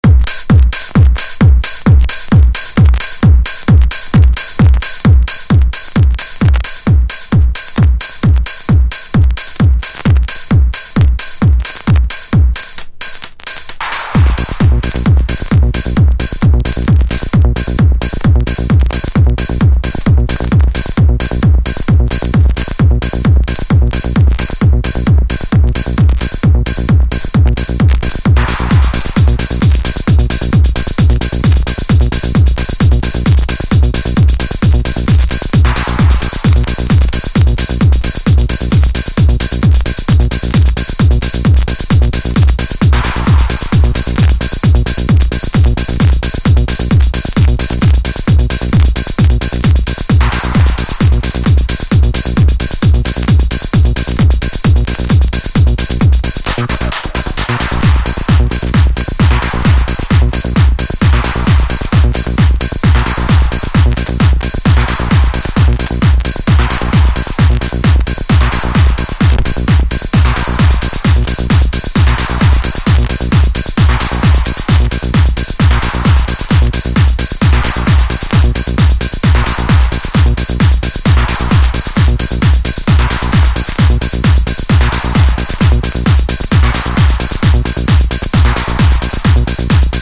伸びのあるシンセとダイナミックなキック音がたまりません！